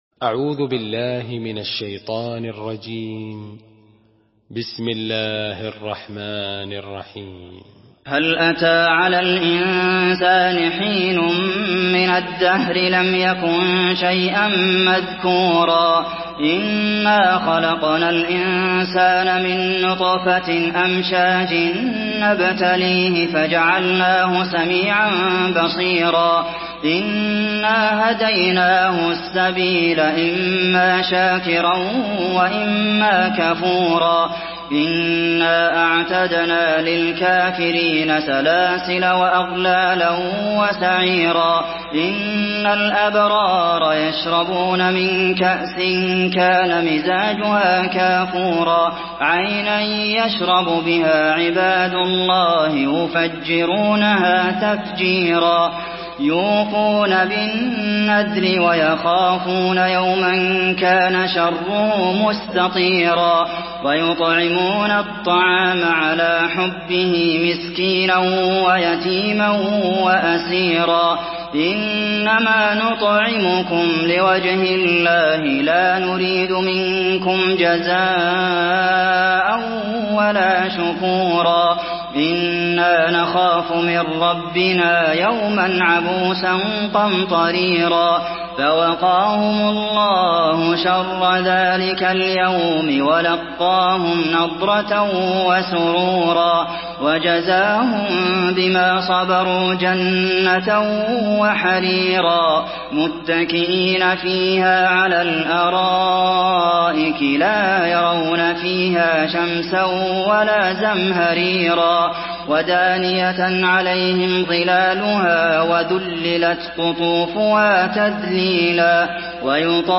سورة الإنسان MP3 بصوت عبد المحسن القاسم برواية حفص
مرتل